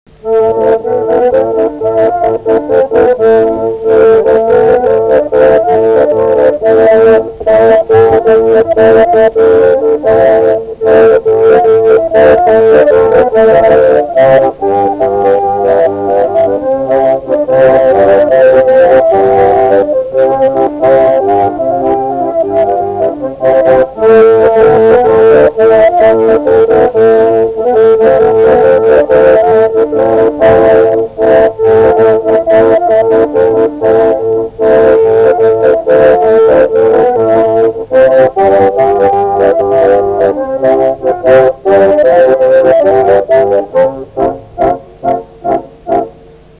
Polka